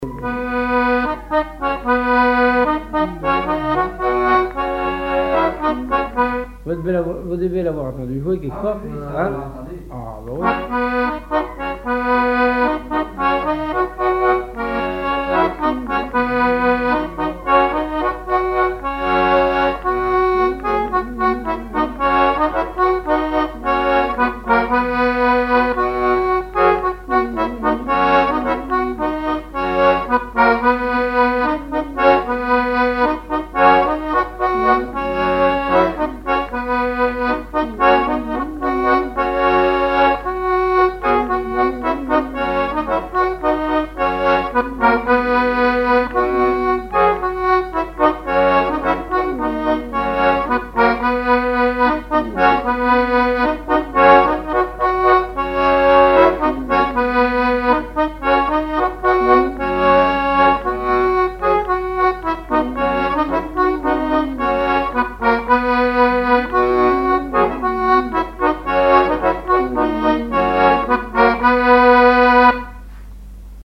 Saint-Hilaire-de-Riez
Fonction d'après l'analyste gestuel : à marcher
accordéon diatonique
Pièce musicale inédite